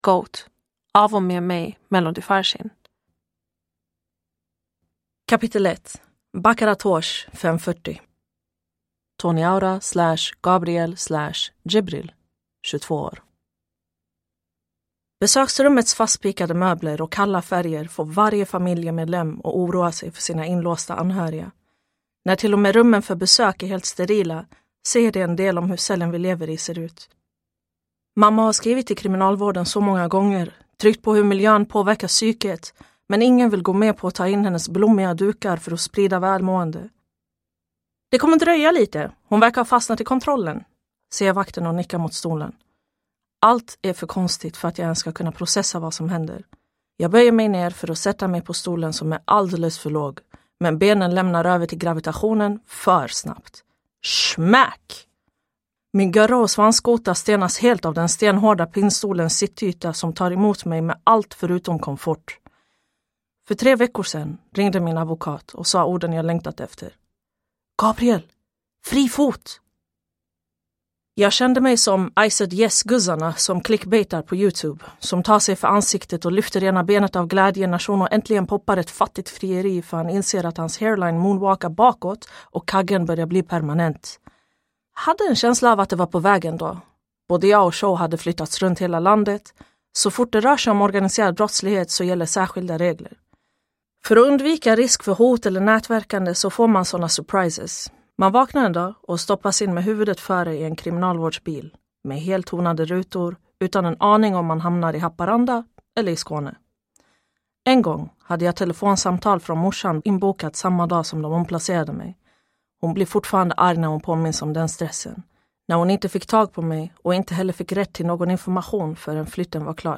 GOAT – Ljudbok